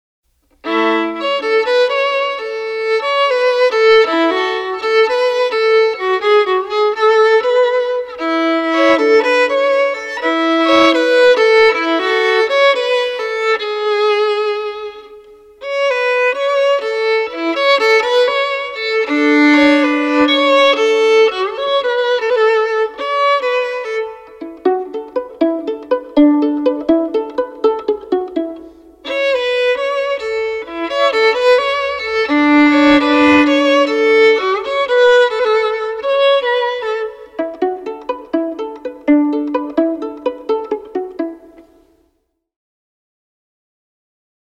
Solo violin tracks recorded at FTM Studio in Denver Colorado